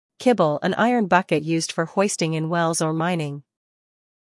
英音/ ˈkɪbl / 美音/ ˈkɪbl /